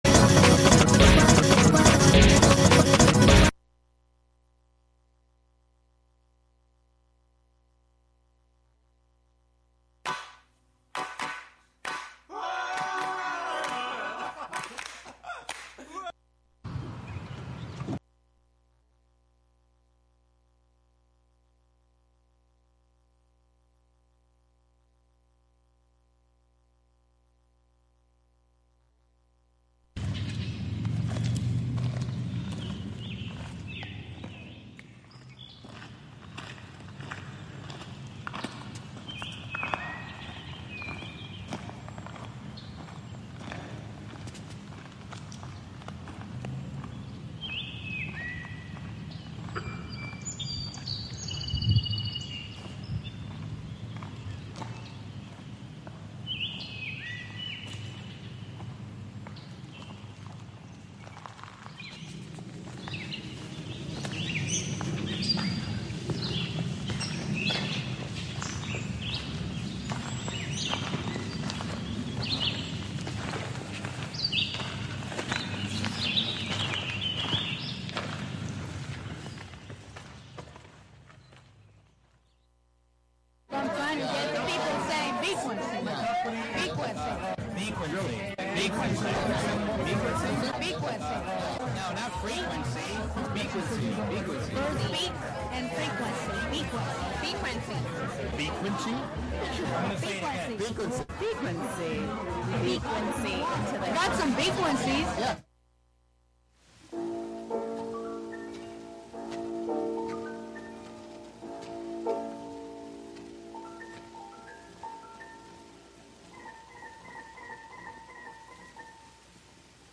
"Beakuency" invites the local community to be inspired by the joy, beauty, and wisdom bird enthusiasts find in the nature of our neighborhoods. Every episode features an interview with local bird people, plus a freeform mix of sound made by birds and humans inspired by birds, and “Birds of Wave Farm,” a field recording journal from Wave Farm, in Acra, New York.